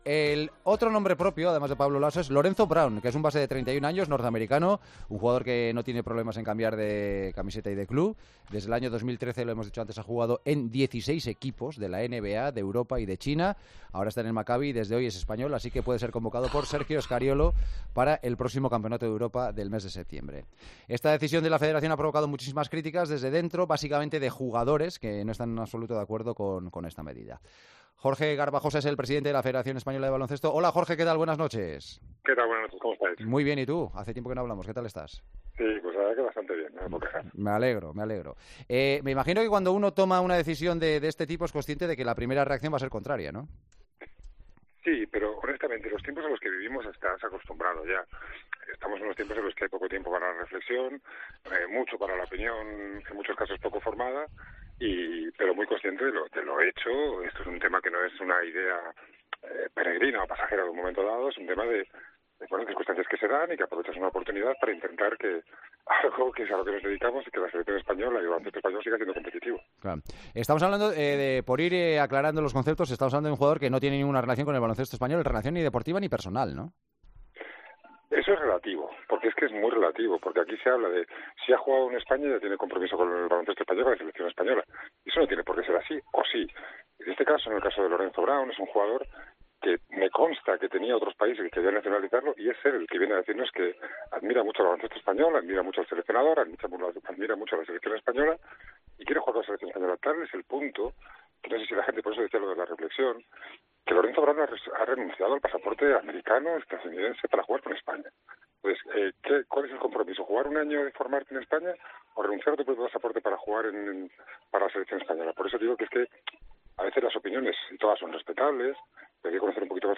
Jorge Garbajosa, presidente de la FEB, y Alfonso Reyes, presidente del sindicato de jugadores, debatieron cara a cara en El Partidazo de COPE sobre la...